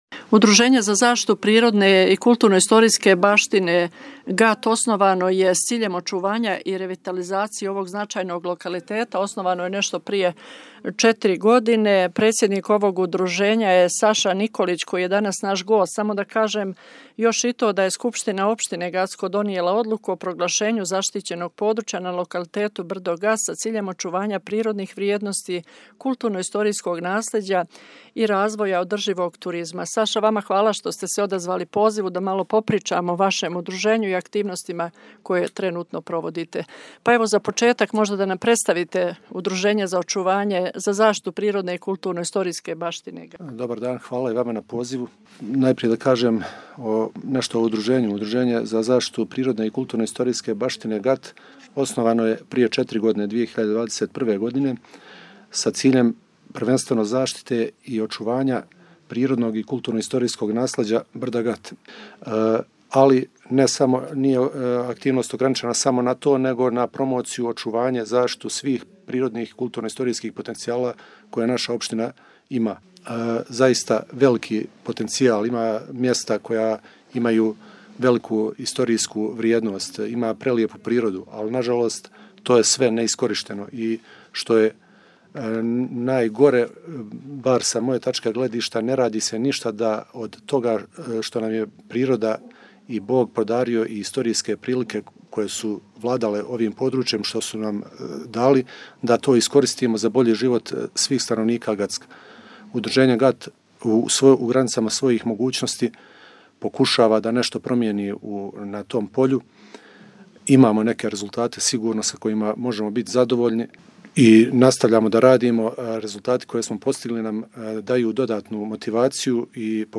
Опширније у разговору